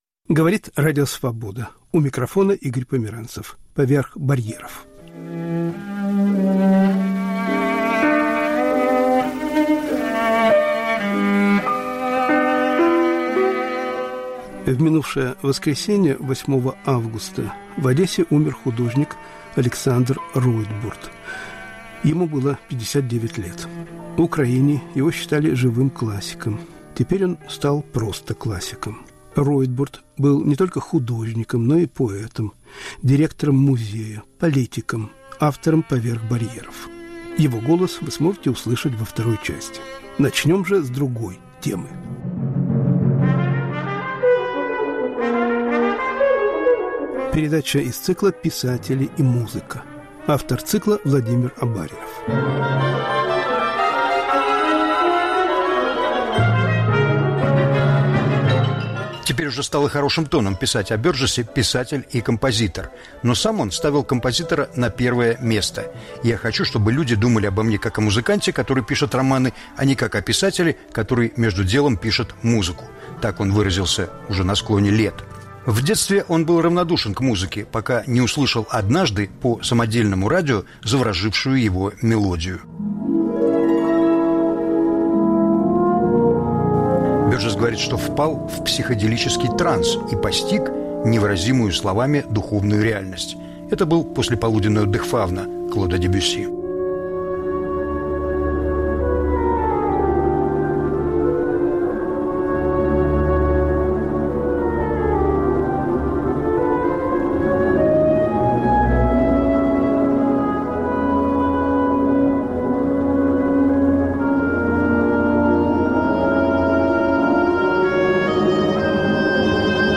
Передача из цикла "Писатели и музыка".*** Памяти художника Александра Ройтбурда (1961-2021). Записи разных лет.